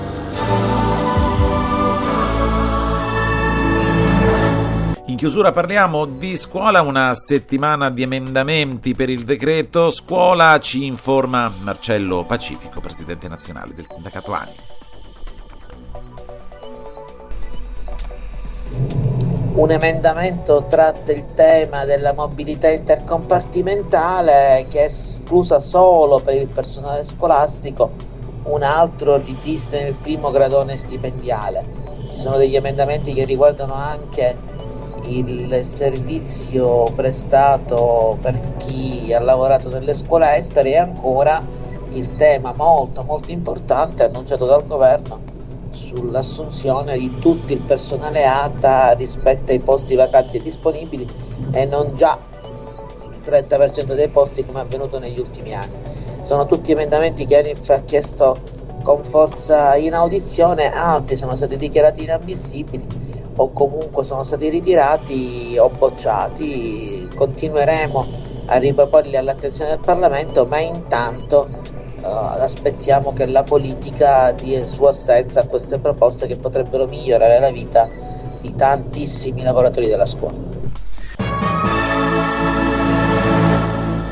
un’intervista ai microfoni di Italia stampa: il leader del sindacato ha illustrato brevemente le proposte emendative che sono state suggerite.